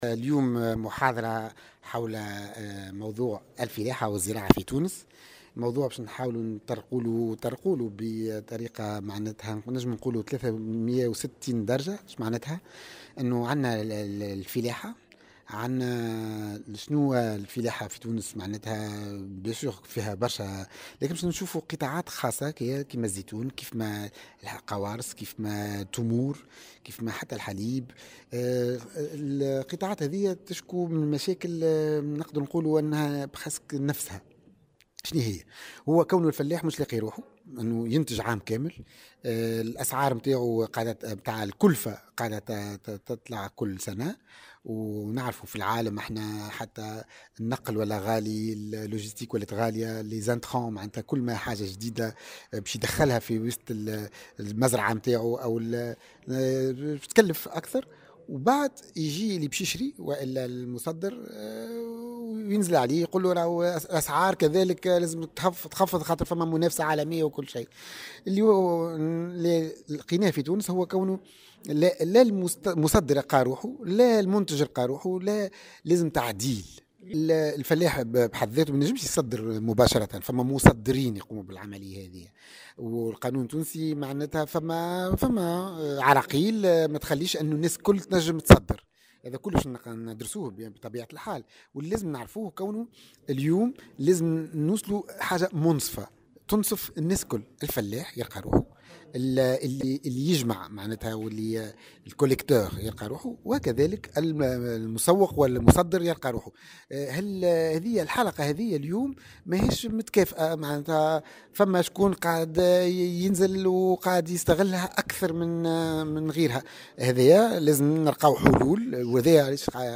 انعقدت مساء اليوم الخميس، ندوة علمية بأحد نزل مدينة صفاقس، تم خلالها طرح أهم المشاكل التي يعاني منها قطاع الفلاحة في تونس.